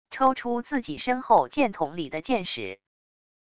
抽出自己身后箭筒里的箭矢wav音频